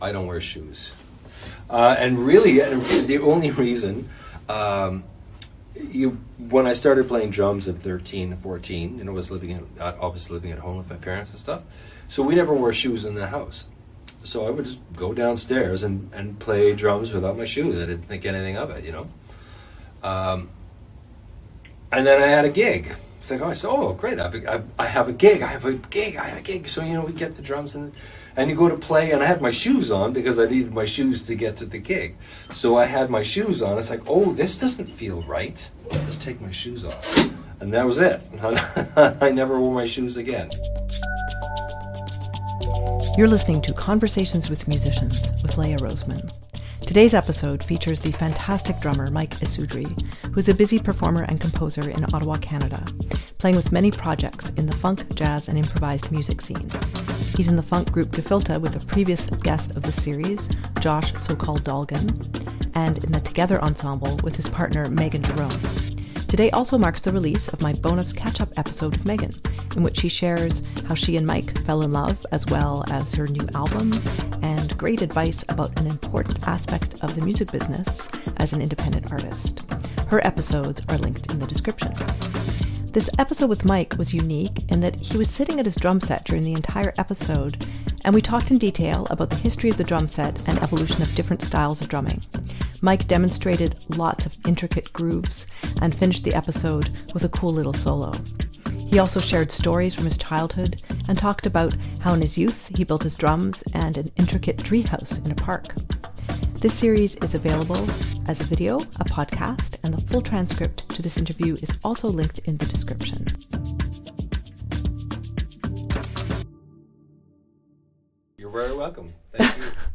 (00:02:12) drum set history with demonstrations: bass drum, ride cymbal, Zildjian, Ludwig, Beatles, speed-metal (00:12:51) tom-toms with demonstration